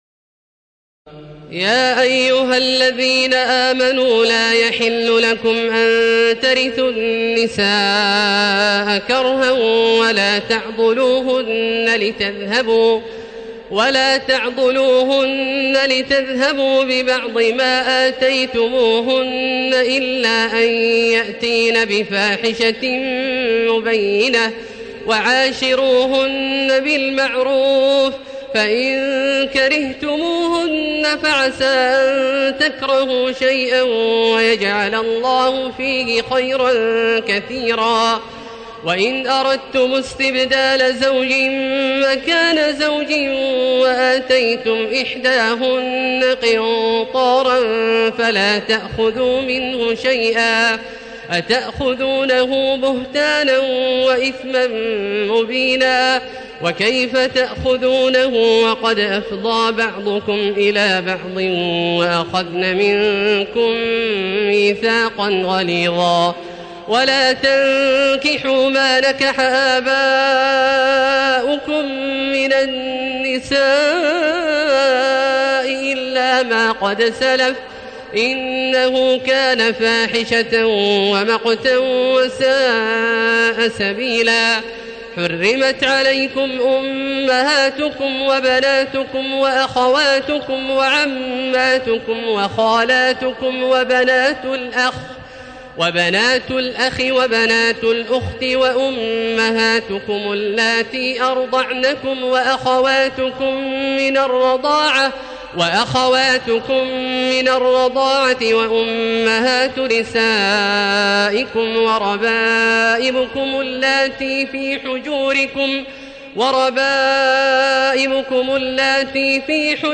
تراويح الليلة الرابعة رمضان 1437هـ من سورة النساء (19-87) Taraweeh 4 st night Ramadan 1437H from Surah An-Nisaa > تراويح الحرم المكي عام 1437 🕋 > التراويح - تلاوات الحرمين